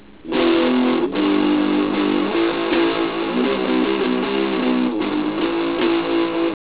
my latest attempt at making an ass of myself via the guitar.